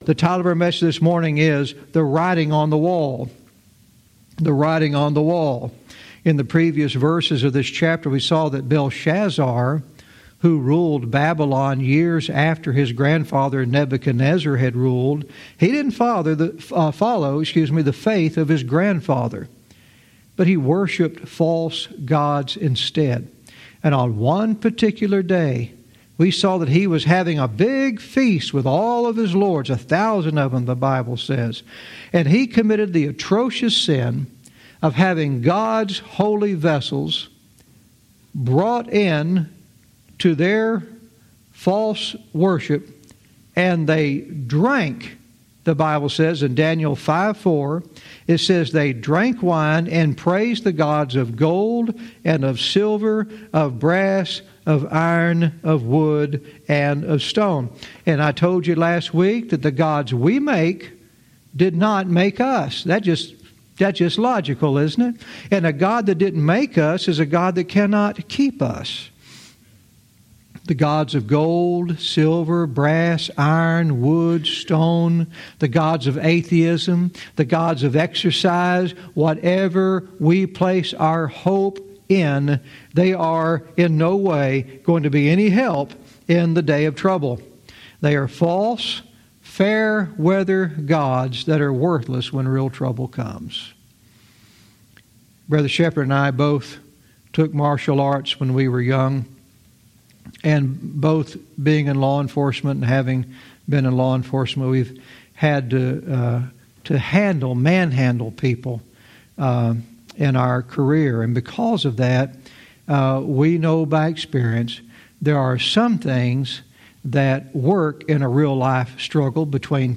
Verse by verse teaching - Daniel 5:5-6 "The Writing on the Wall"